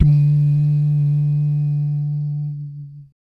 Index of /90_sSampleCDs/ILIO - Vocal Planet VOL-3 - Jazz & FX/Partition B/3 BASS DUMS